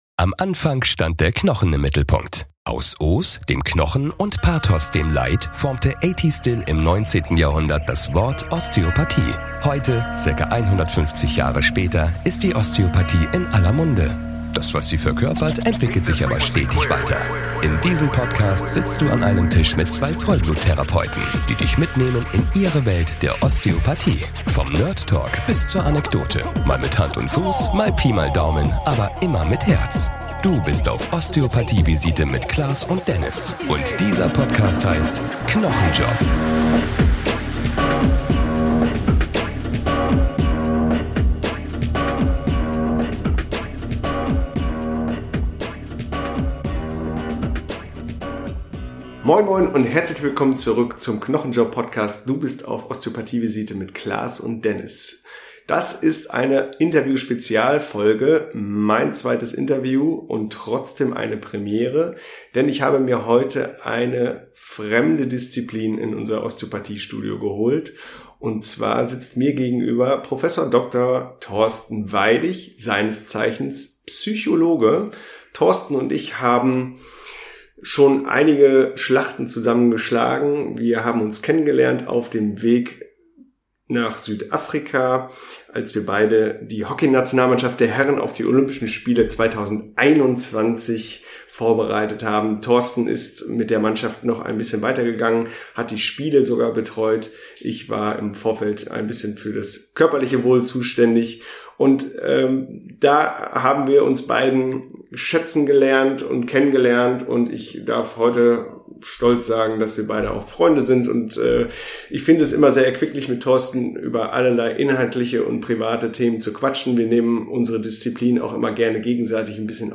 Interview-Spezial